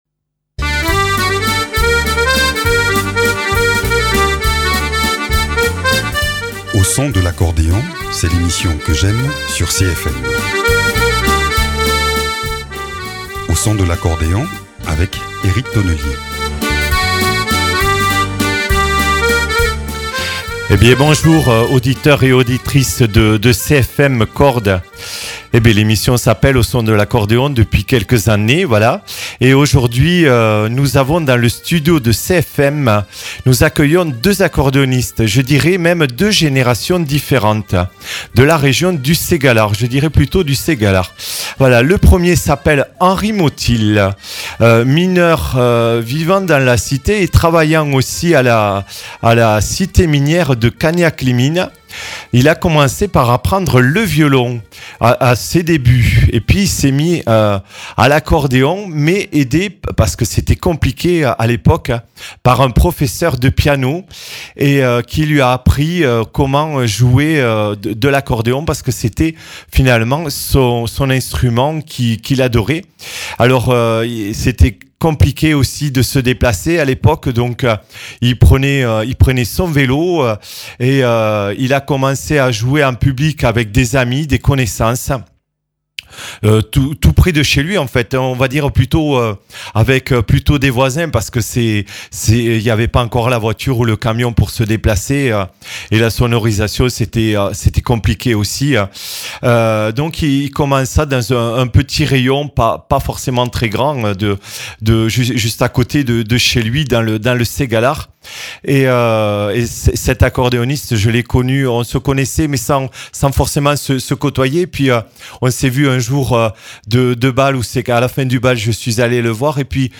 Deux générations d’accordéonistes pour ce numéro de la rentrée. Il nous raconte leurs rencontres, leurs pratiques de l’accordéon et nous avons le plaisir d’entendre quelques uns de leurs morceaux !